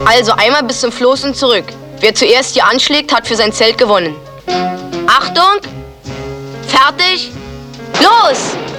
Wettkampf-Richter  - ?????
Und diese relativ markante Stimme